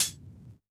Index of /musicradar/Kit 8 - Vinyl
CYCdh_VinylK1-ClHat02.wav